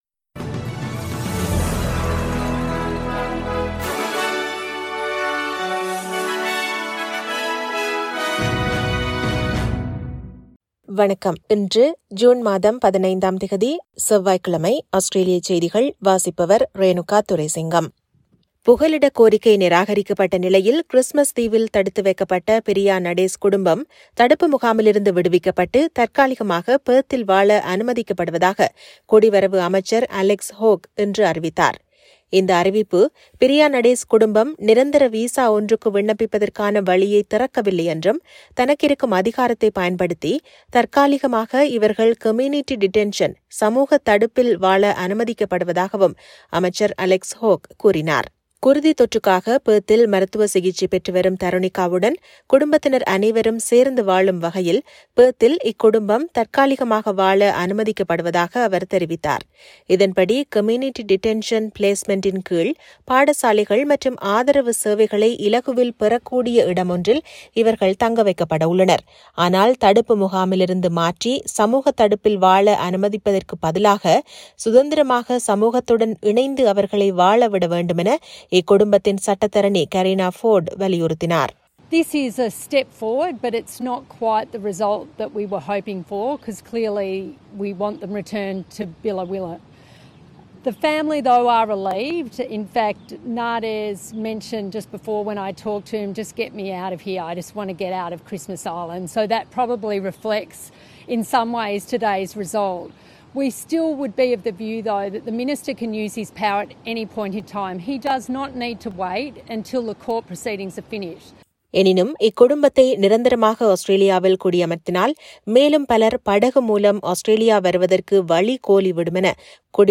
SBS தமிழ் ஒலிபரப்பின் இன்றைய (செவ்வாய்க்கிழமை 15/06/2021) ஆஸ்திரேலியா குறித்த செய்திகள்.